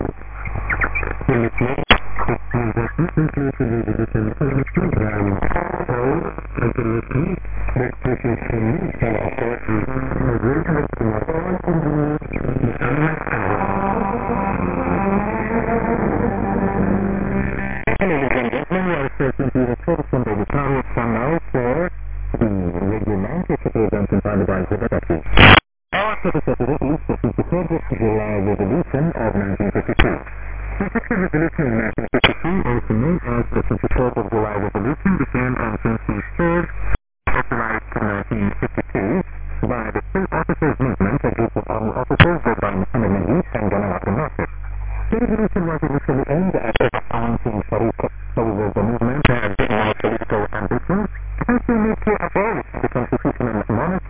Radio_Cairo_voice_noises_02